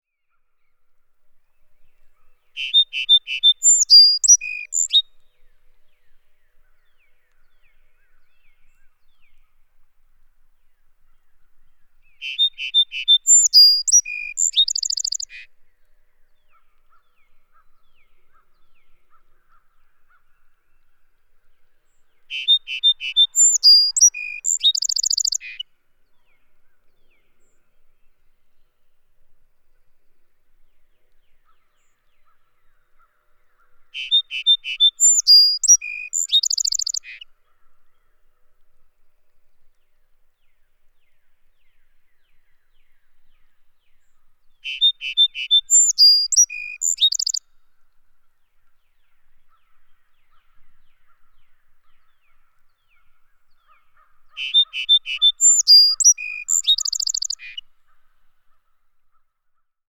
The songs of some species, like the song sparrow, follow the form of a sonata, beginning with a strong theme, then the theme is musically played with, and for a finish, the original theme is then repeated.